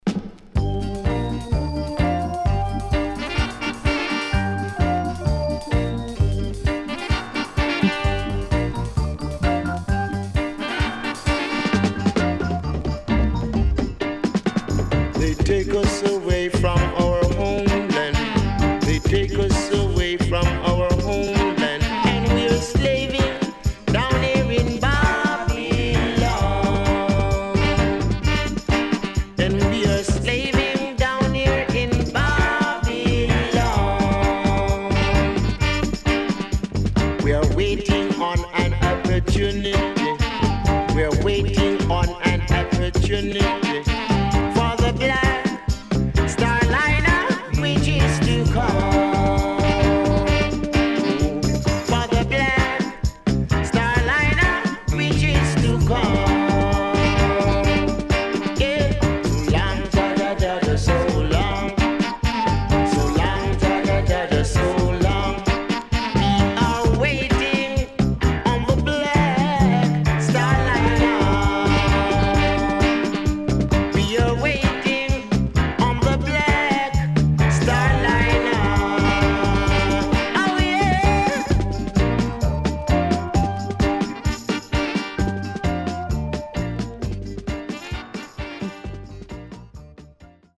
SIDE B:少しジリジリしたノイズ入りますが良好です。